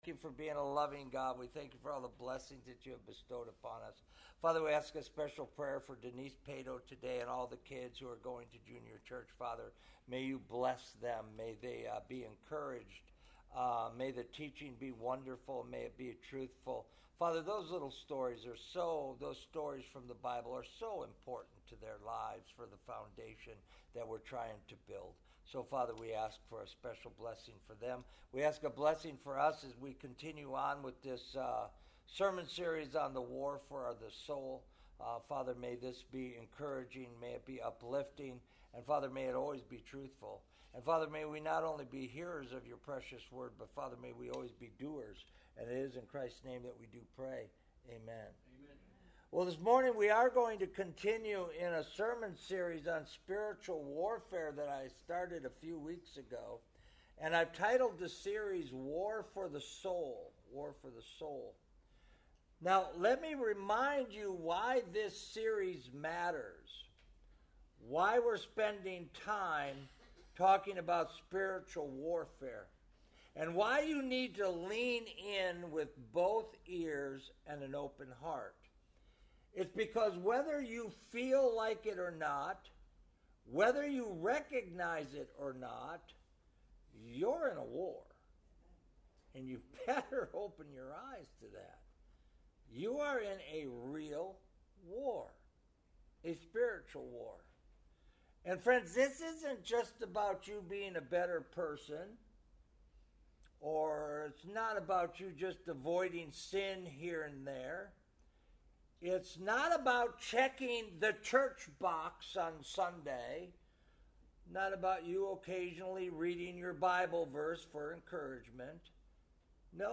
Sermons
This page provides online versions of Sunday worship services.